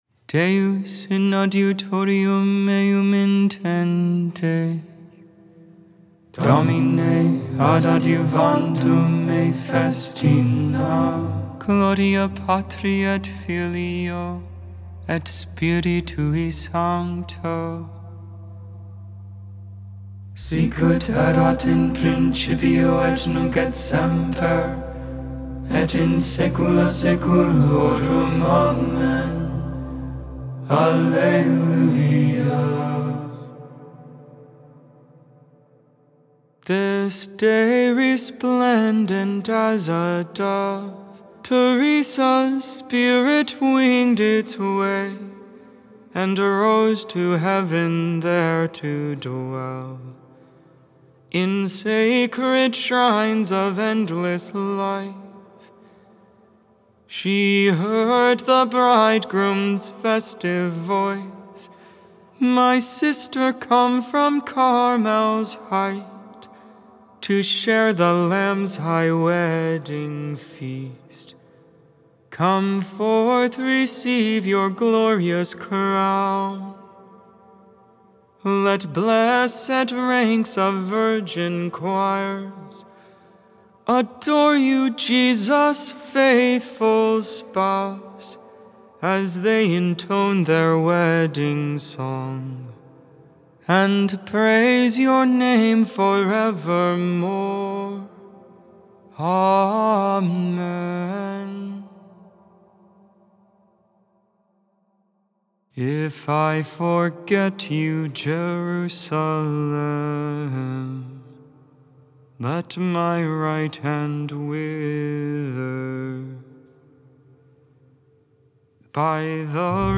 10.15.24 Vespers, Tuesday Evening Prayer